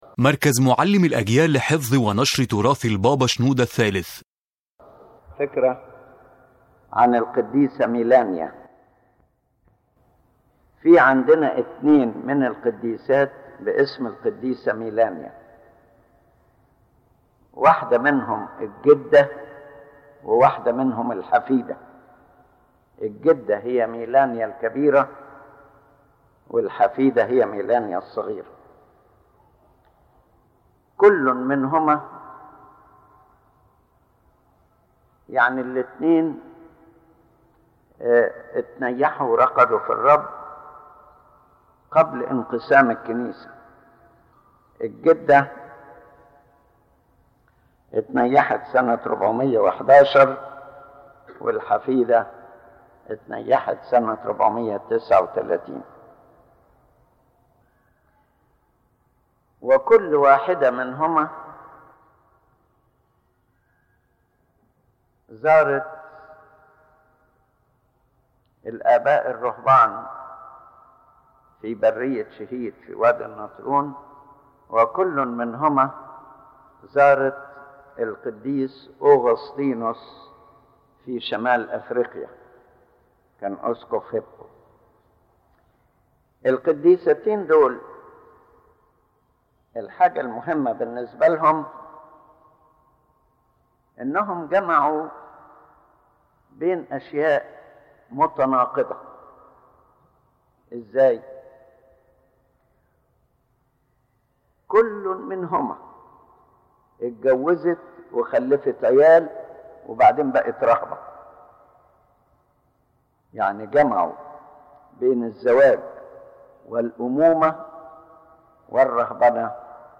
يتحدث قداسة البابا شنوده الثالث عن قدّيستين تحملان اسم ميلانيا: ميلانيا الكبيرة (الجدة) وميلانيا الصغيرة (الحفيدة). وقد عاشت كلتاهما قبل انقسام الكنيسة، واتسمت حياتهما بالقداسة والجهاد الروحي العميق.
⬇ تحميل المحاضرة أولًا: التعريف بالقديستين يتحدث قداسة البابا شنوده الثالث عن قدّيستين تحملان اسم ميلانيا: ميلانيا الكبيرة (الجدة) و ميلانيا الصغيرة (الحفيدة) .